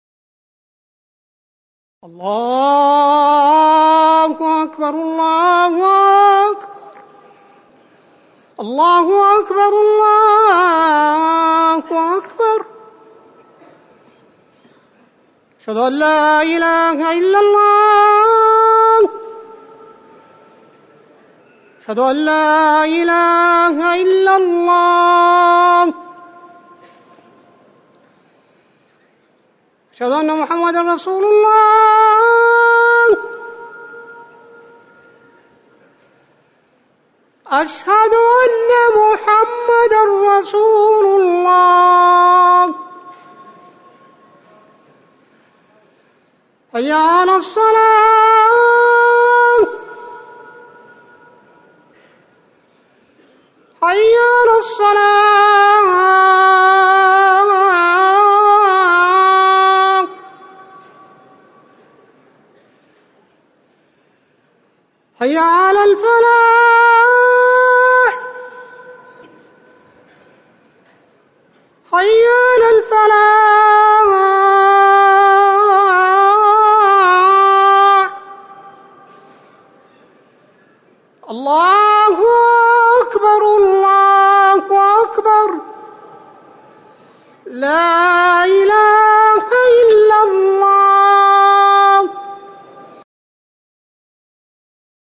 أذان المغرب - الموقع الرسمي لرئاسة الشؤون الدينية بالمسجد النبوي والمسجد الحرام
تاريخ النشر ٢١ صفر ١٤٤١ هـ المكان: المسجد النبوي الشيخ